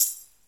normal-slidertick.ogg